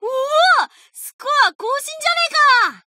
贡献 ） 协议：Copyright，其他分类： 分类:伏特加(赛马娘 Pretty Derby)语音 您不可以覆盖此文件。